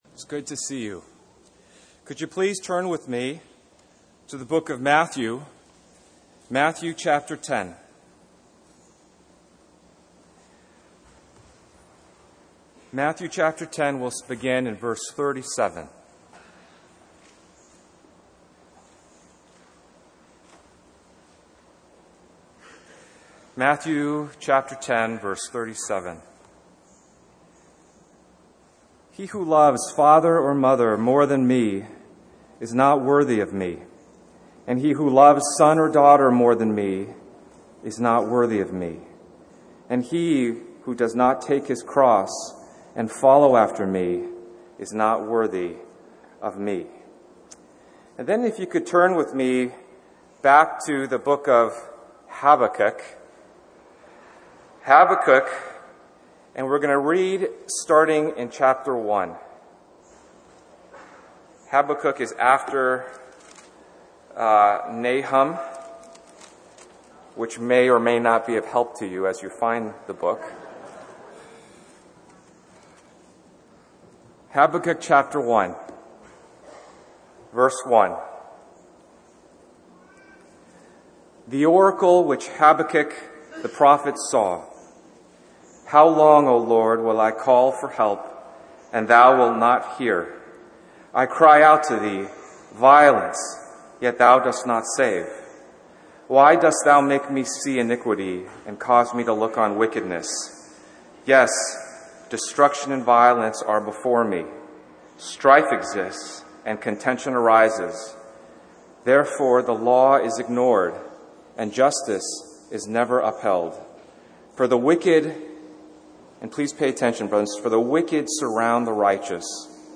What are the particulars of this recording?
Harvey Cedars Conference We apologize for the poor quality audio